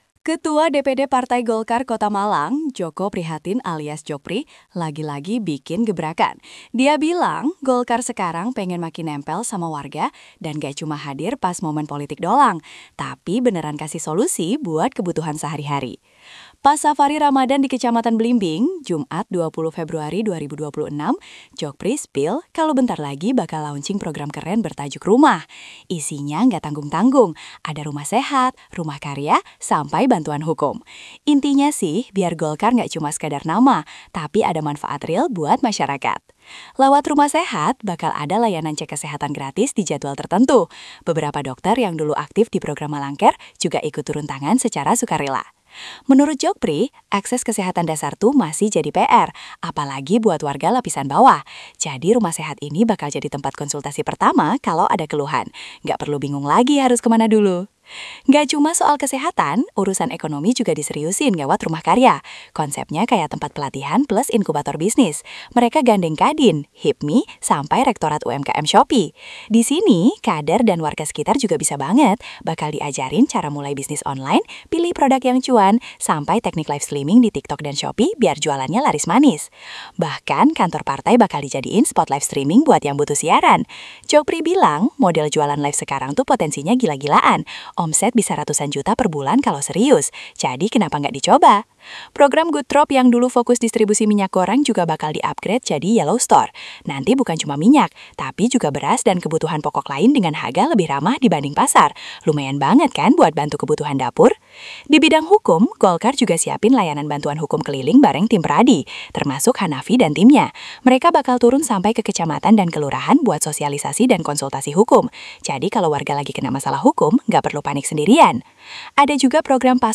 Suasana semangat golkar kecamatan Blimbing di acara Safari Ramadhan.